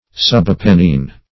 Subapennine \Sub*ap"en*nine\, a.
subapennine.mp3